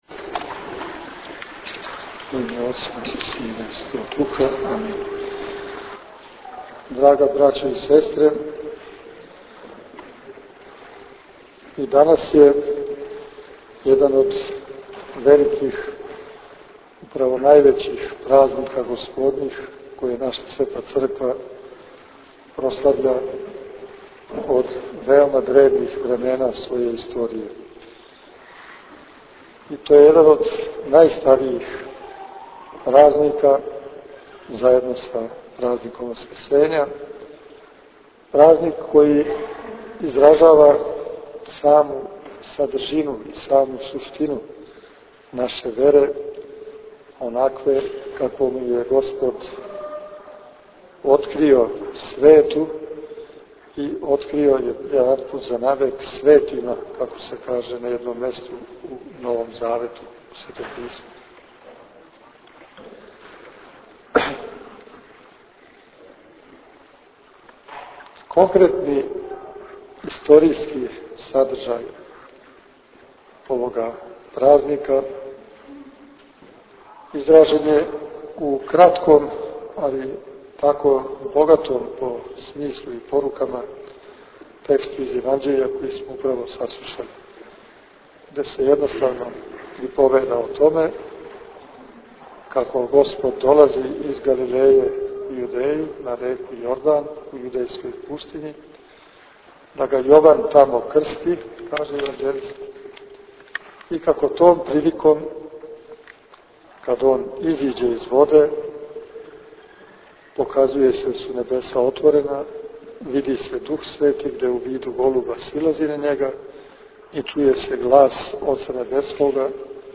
Његово Преосвештенство Епископ бачки Господин др Иринеј предводио је богојављенско литургијско славље у храму Светог великомученика Георгија у Новом Саду.
Звучни запис беседе Епископа Иринеја
vladika_irinej_bogojavljenje_2010.mp3